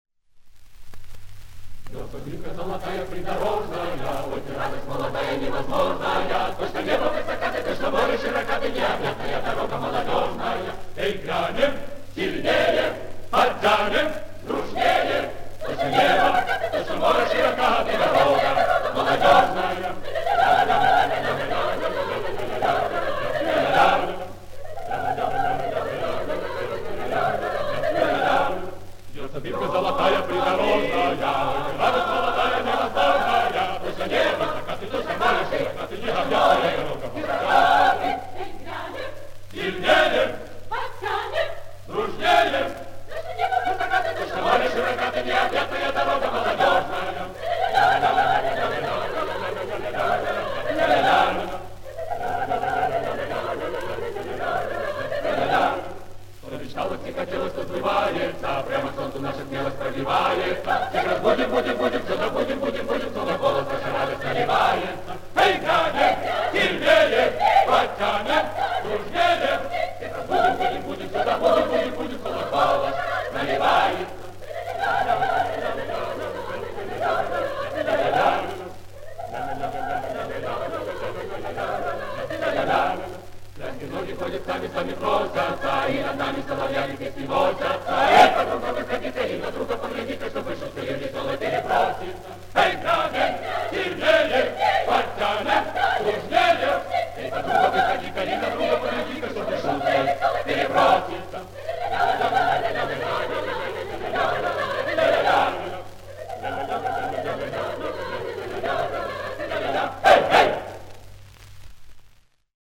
Замечательная акапелльная обработка